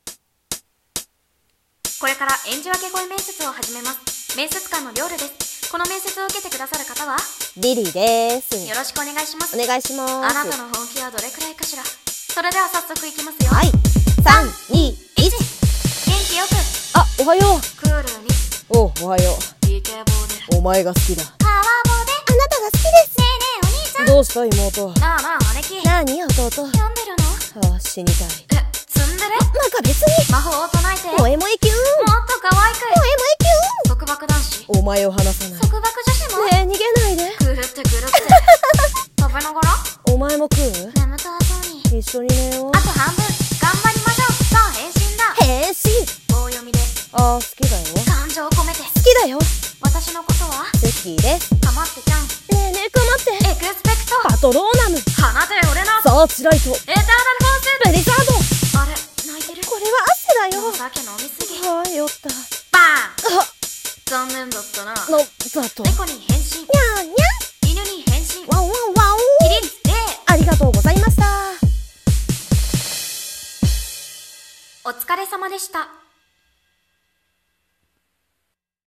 【声面接】演じ分け声面接